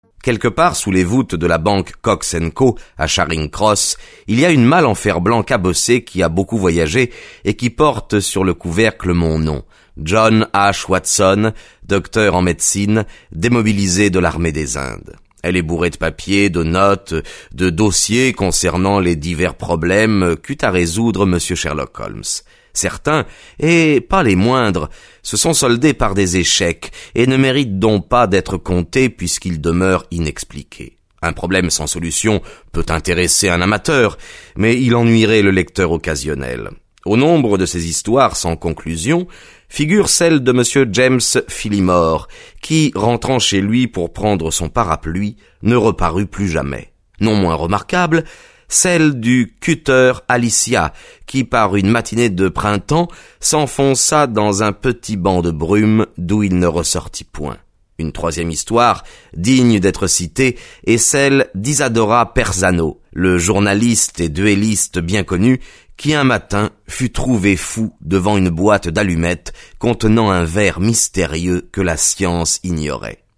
Click for an excerpt - Sherlock Holmes - Le pont de Thor de Arthur Conan Doyle